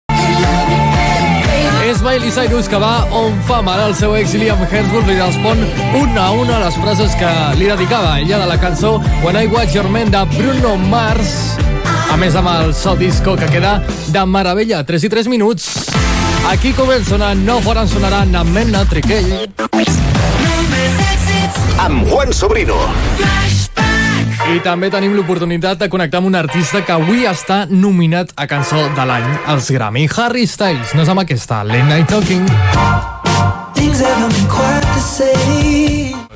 Tema musical, hora, indicatiu del programa i tema musical
Musical
FM